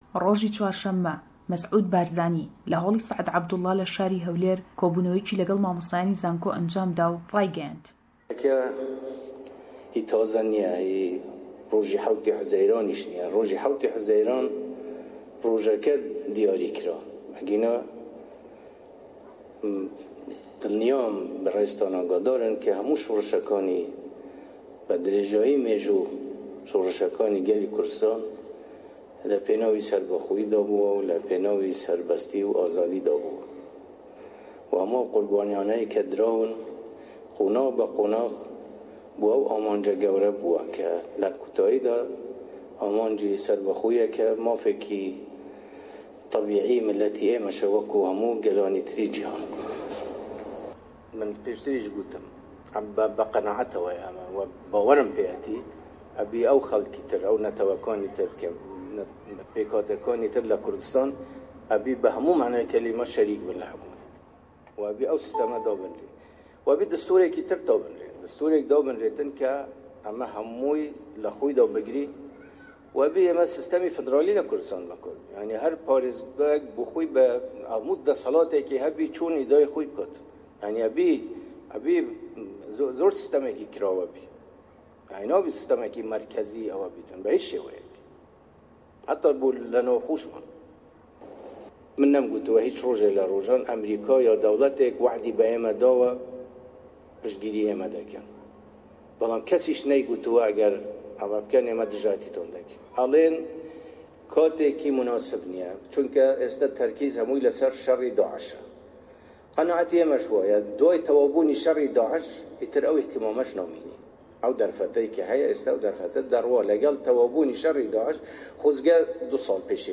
Le em raportey peyamnêrman le Sîlêmanî ye we; Serokî Herêmî Kurdistan le gell mamostayanî zanko kodebête we, offîsî NRT le Dihok hêriş dekrête serî, û çendî hewallî dîkey nawçeke.